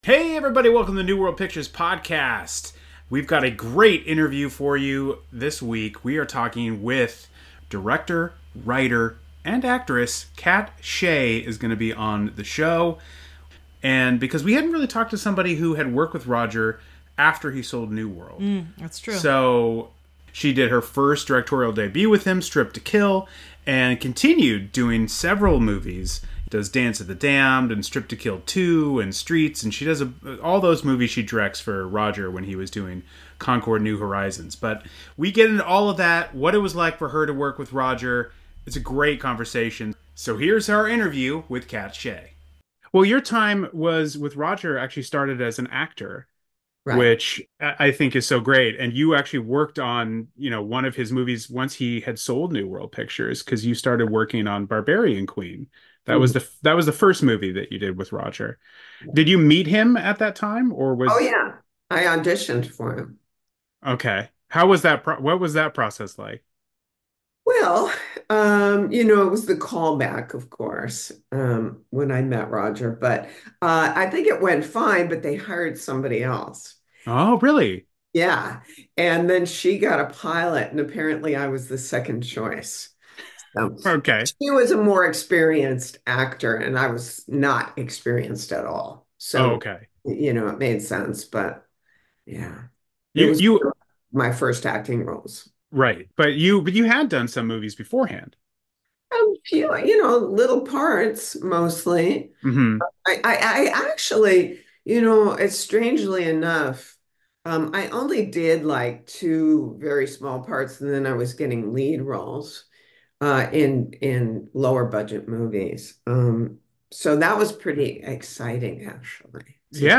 Bonus Episode: Interview with director/writer/actor Katt Shea (STRIPPED TO KILL, POISON IVY)